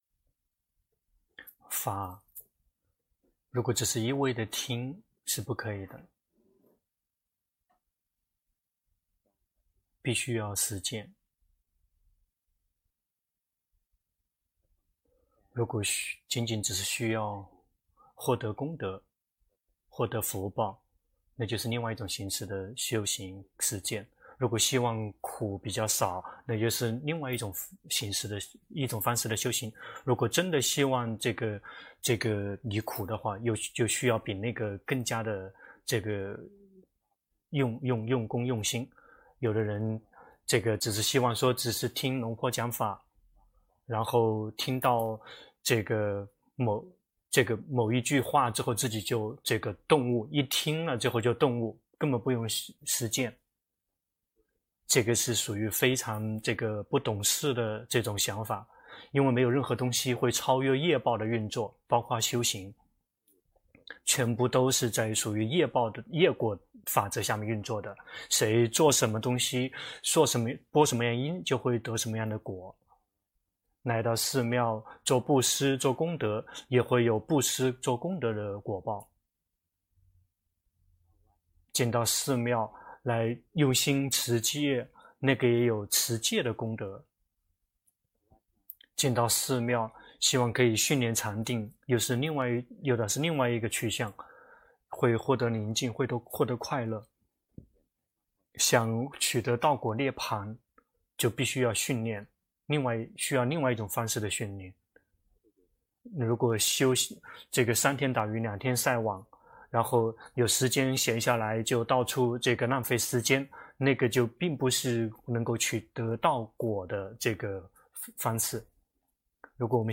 泰國解脫園寺 同聲翻譯